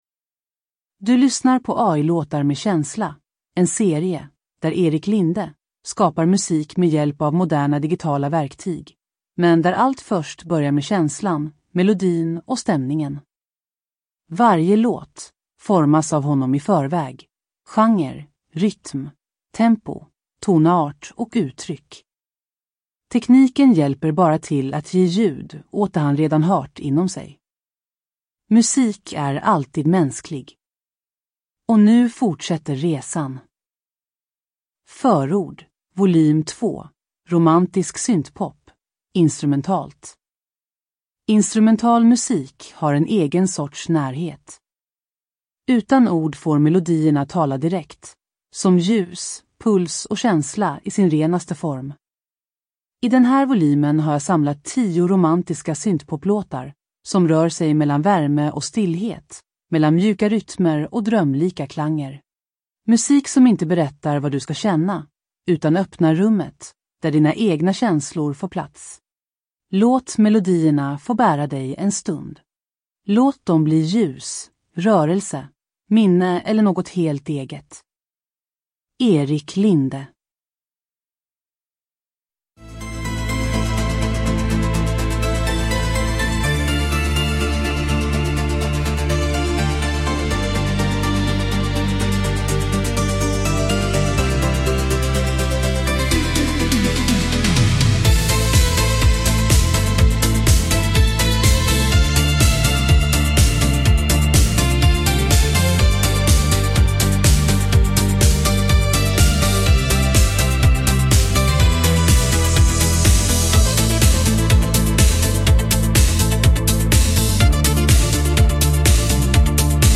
Det här är musik som inte behöver ord.